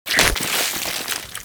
Enemydie.mp3